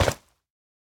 Minecraft Version Minecraft Version 1.21.5 Latest Release | Latest Snapshot 1.21.5 / assets / minecraft / sounds / block / soul_soil / step3.ogg Compare With Compare With Latest Release | Latest Snapshot